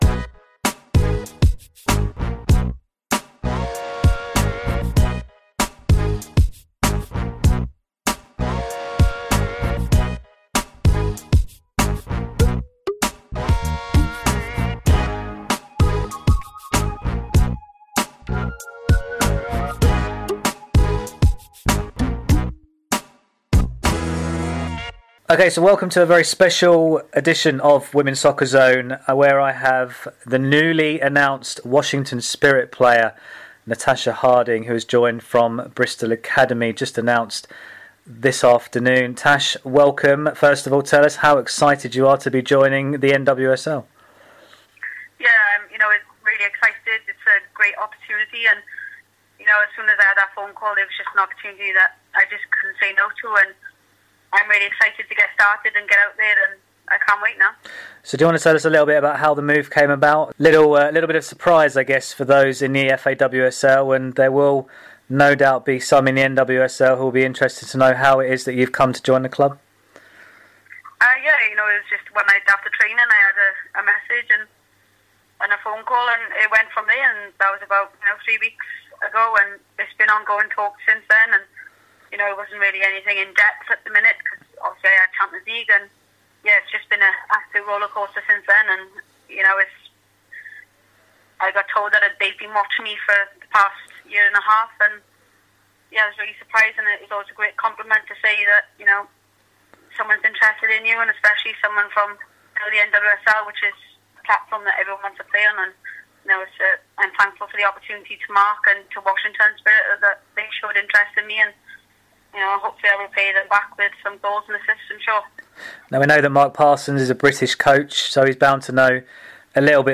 Exclusive interview with newly recruited Washington Spirit forward, Natasha Harding.